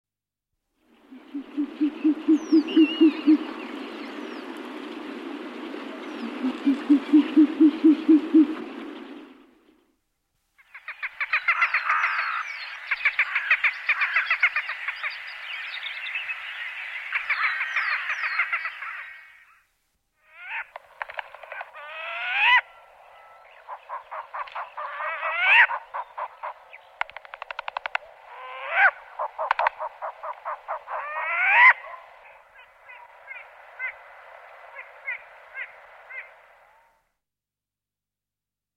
Назад в Пение птиц
Файл 24 Болотная сова (Asio flammeus).mp3
Болотная сова, или степная сова (устар.) — Asio flammeus
Голос. Глухая барабанная дробь.